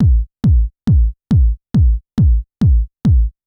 BD        -L.wav